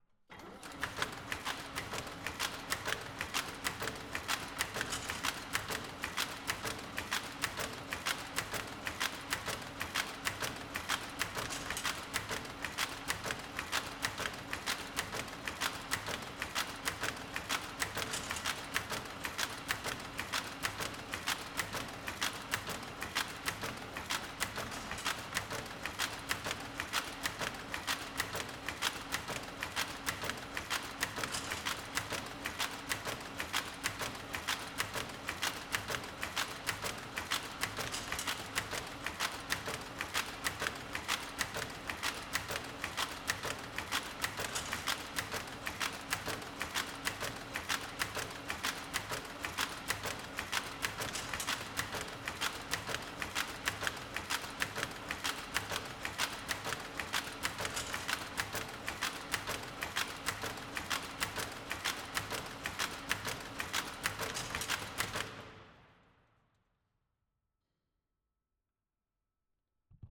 Samples Webstühle TIM - Augsburg
Maschine 3_50er Jahre